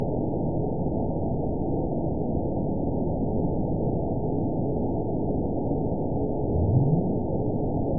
event 917471 date 04/05/23 time 00:24:41 GMT (2 years, 1 month ago) score 9.27 location TSS-AB04 detected by nrw target species NRW annotations +NRW Spectrogram: Frequency (kHz) vs. Time (s) audio not available .wav